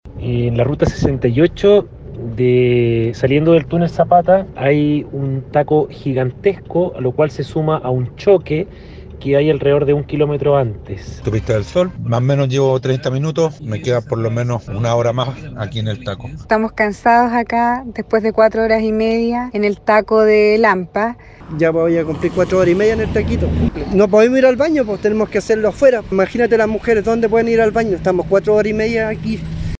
Conductores criticaron la medida que demoró en varias horas el desplazamiento.